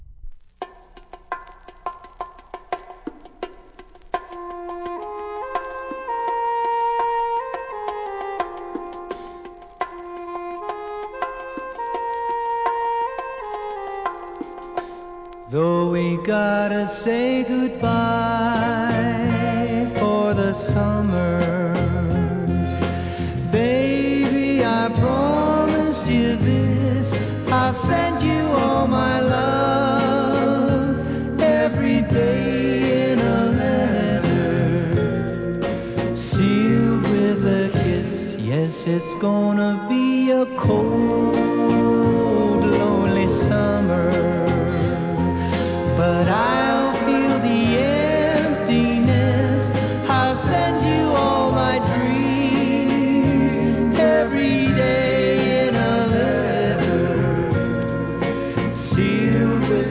¥ 660 税込 関連カテゴリ SOUL/FUNK/etc...